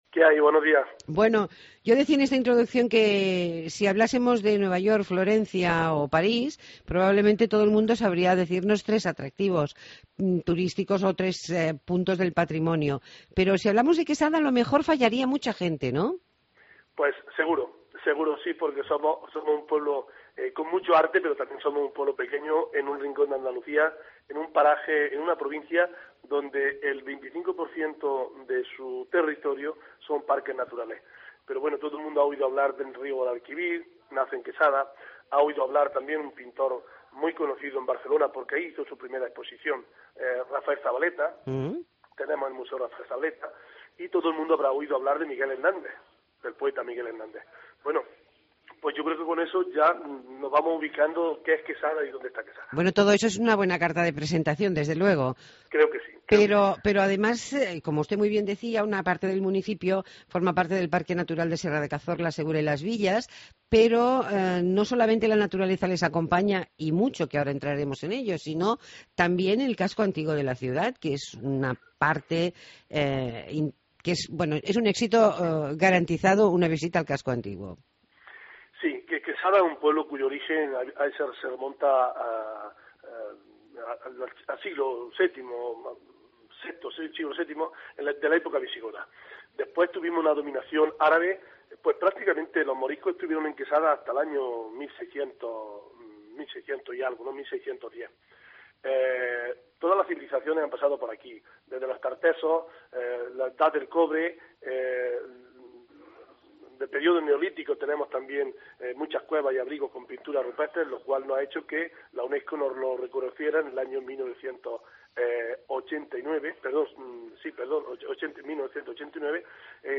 Entrevistamos a Manuel Vallejo, alcalde de Quesada (Jaén) que nos habla de la oferta turística del municipio.